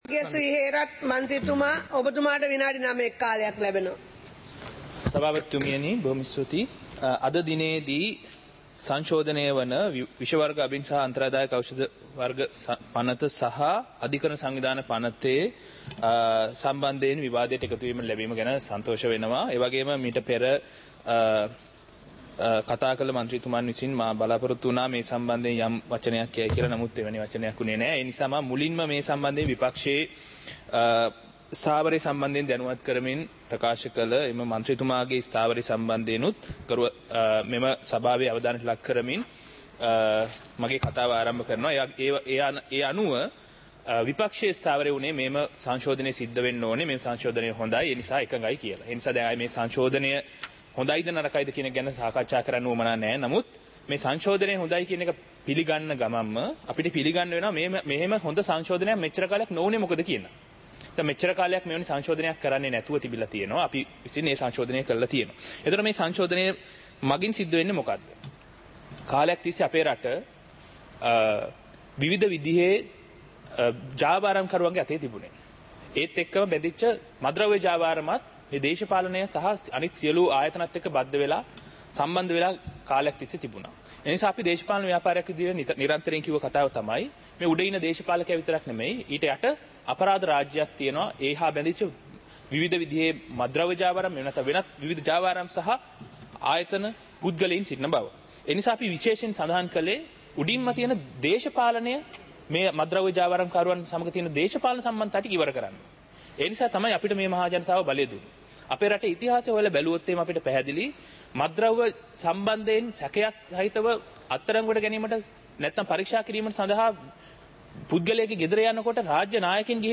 சபை நடவடிக்கைமுறை (2026-02-19)
நேரலை - பதிவுருத்தப்பட்ட